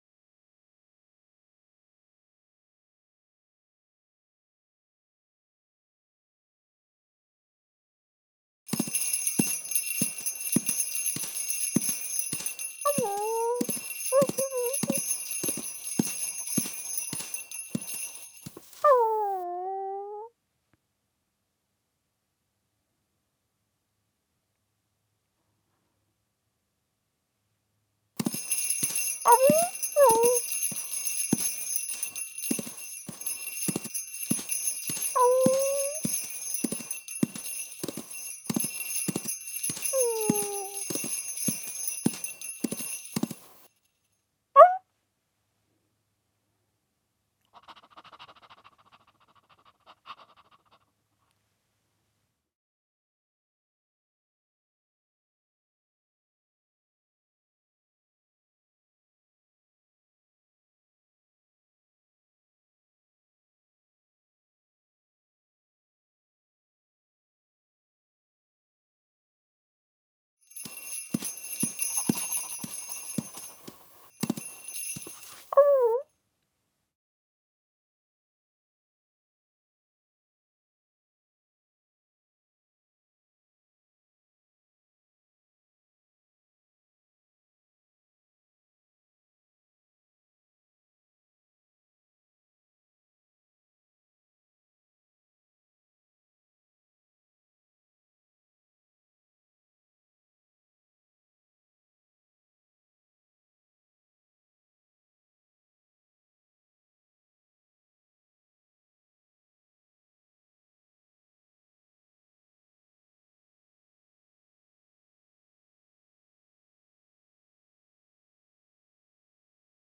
NPC_Scene06_Dog.ogg